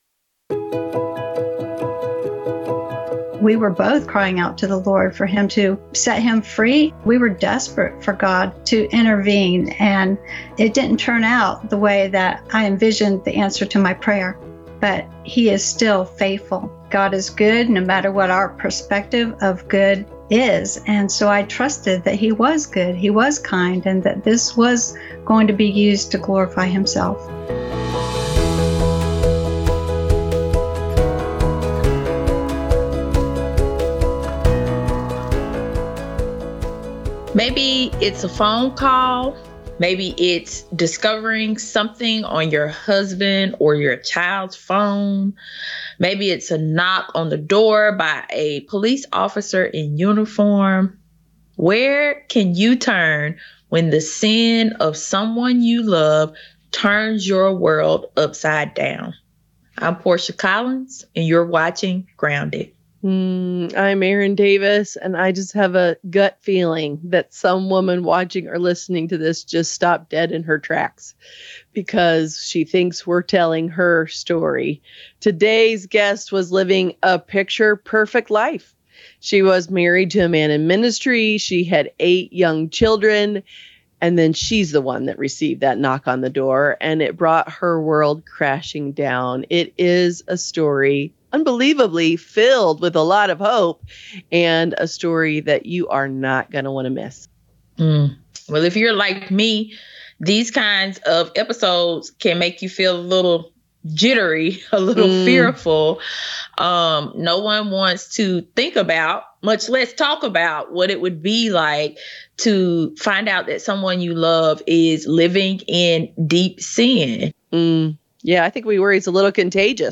If you have been impacted by a loved one’s secret struggles, don’t miss this powerful interview.